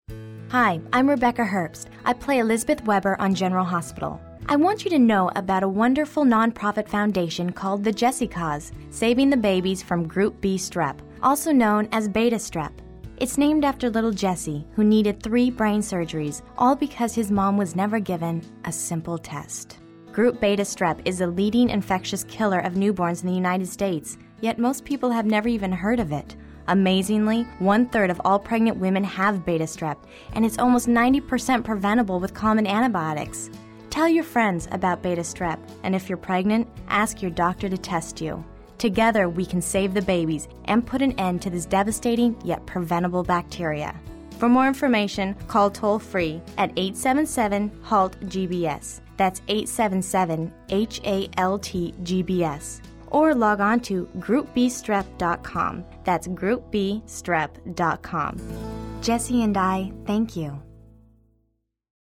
Public Service Announcement
Rebecca plays Elizabeth Weber on General Hospital.